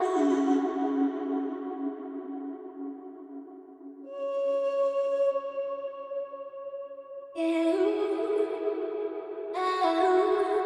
vox1-1